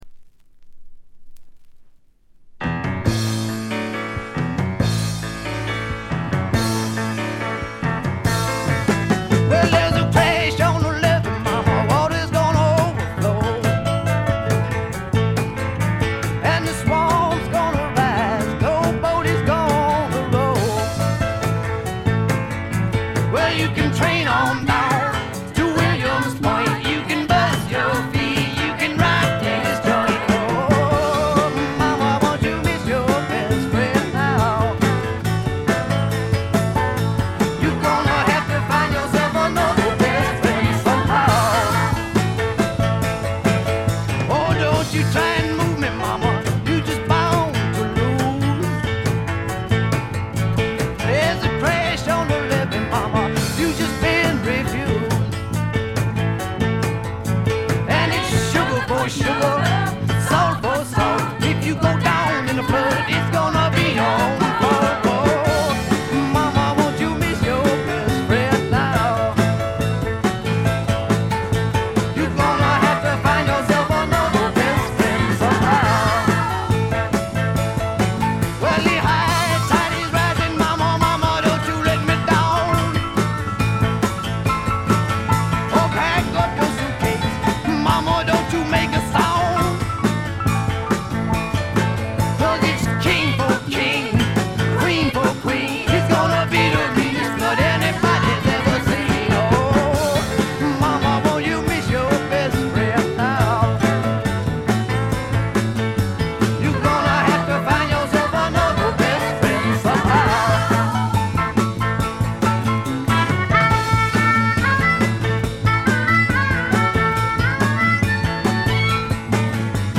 ごくわずかなノイズ感のみ。
試聴曲は現品からの取り込み音源です。